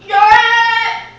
Examples with Downsteps
youre-it-upstep.wav